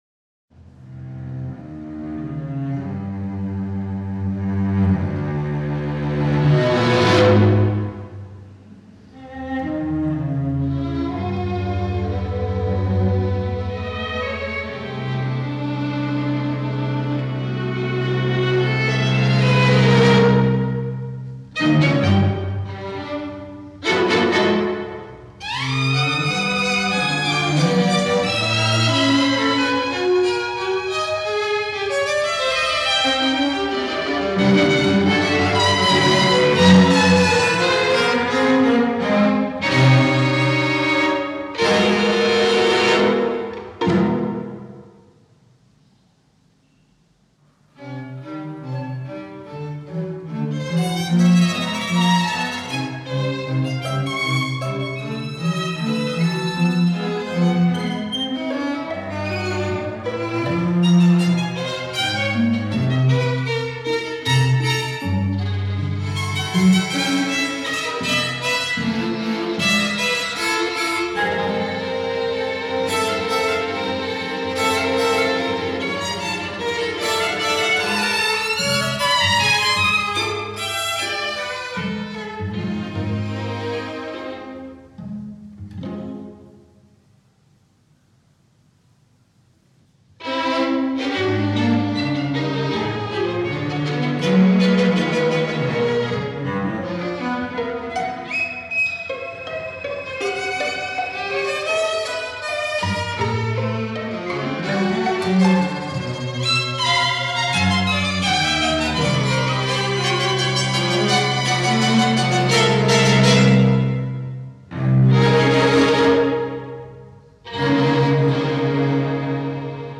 Diez Micropiezas para cuarteto de cuerdas